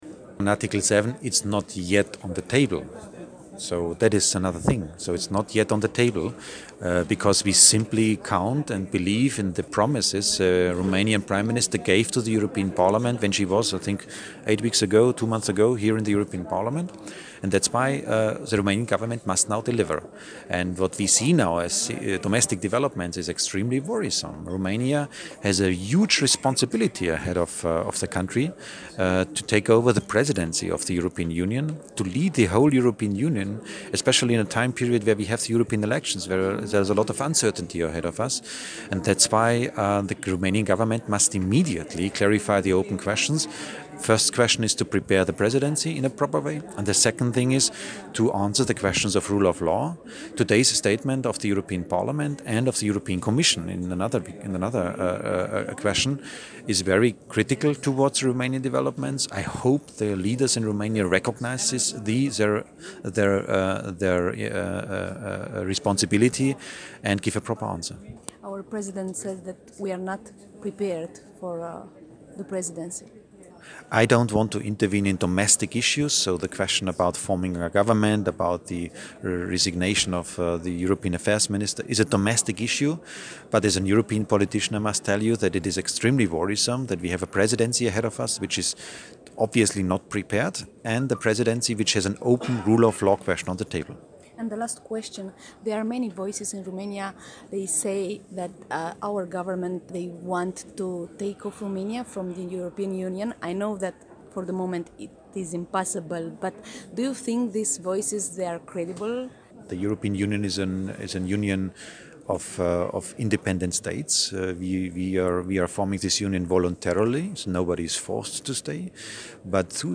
El a atras atenția, totodată, într-o declarație pentru Europa FM, că România are de răspuns unor probleme foarte grave.